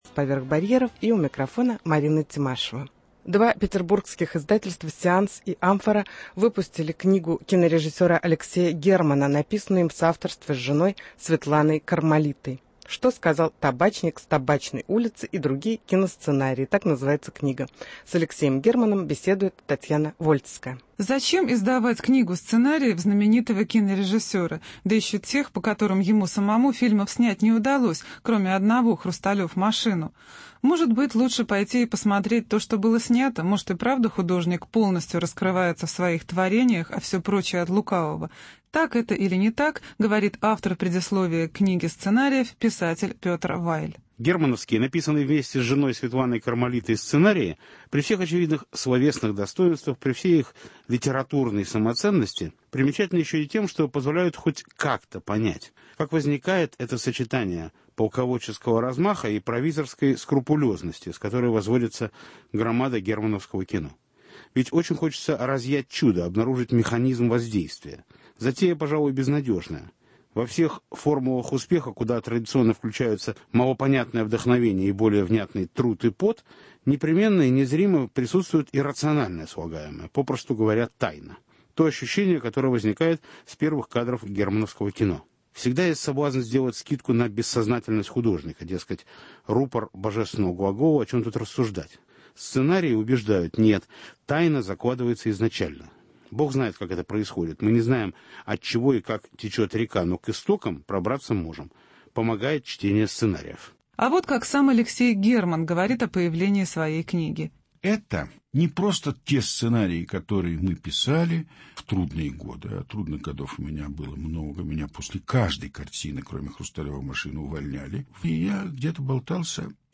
Интервью с Алексеем Германом в связи с изанием книги киносценариев Германа и Кармалиты